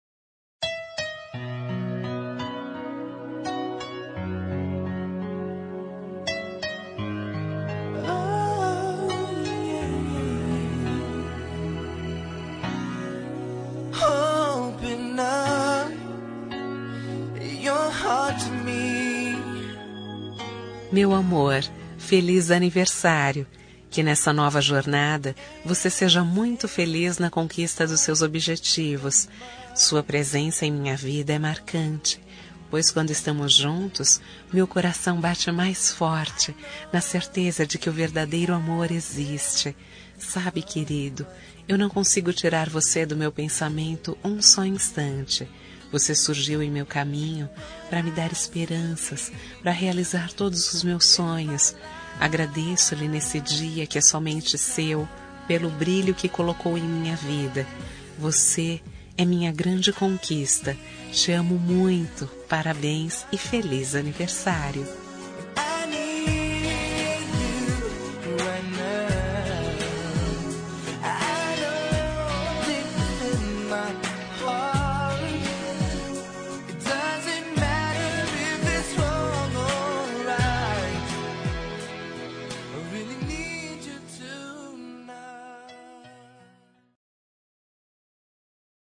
Telemensagem de Aniversário Romântico – Voz Feminina – Cód: 1033